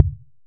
impactMetal_002.ogg